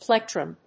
音節plec・trum 発音記号・読み方
/pléktrəm(米国英語)/